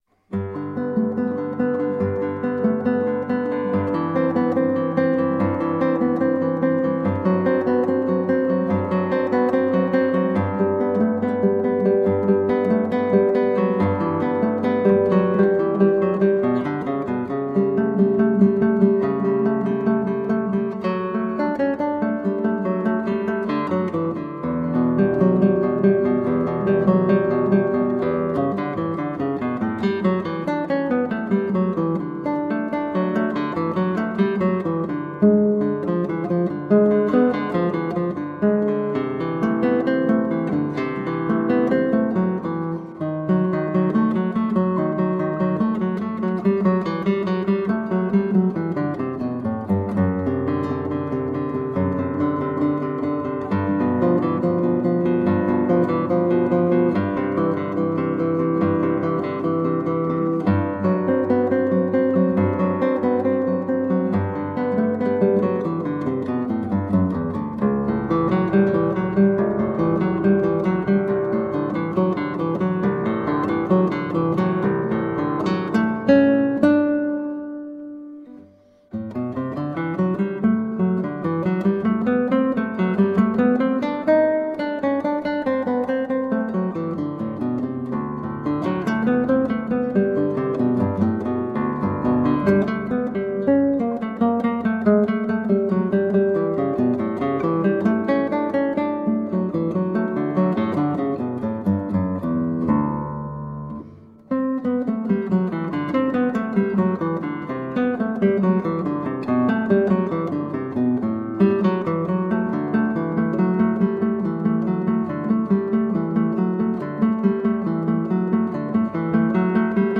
Tagged as: Classical, Baroque, Instrumental
Classical Guitar